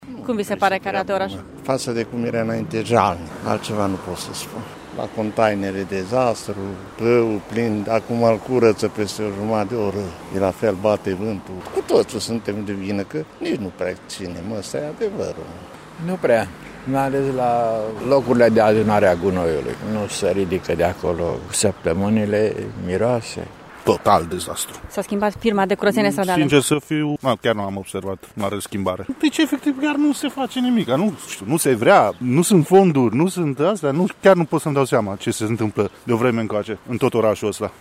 Târgumureșenii sunt foarte nemulțumiți de felul în care se face curățenie și, mai ales, de faptul că ghenele de gunoi sunt permanent pline: